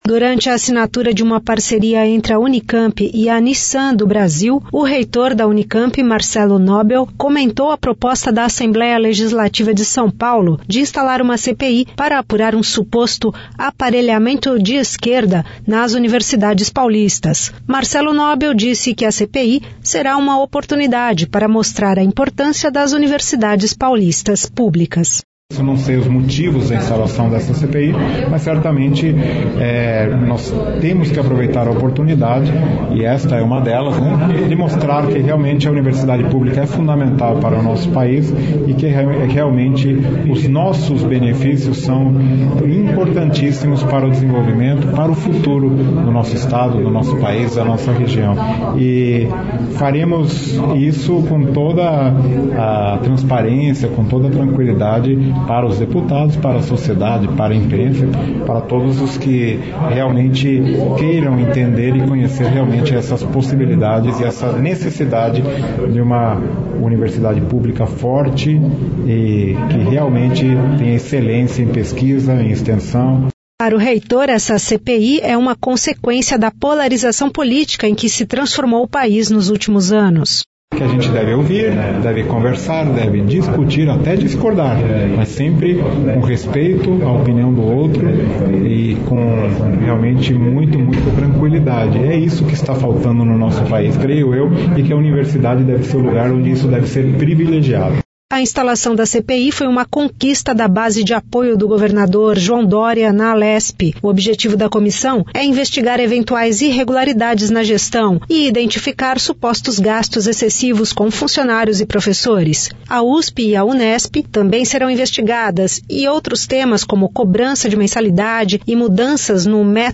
Durante a assinatura de uma parceria entre a Unicamp e a Nissan do Brasil, o reitor da Unicamp, Marcelo Knobel, comentou a proposta da Assembleia Legislativa de São Paulo de instalar uma CPI para apurar um suposto “aparelhamento de esquerda” nas universidades paulistas.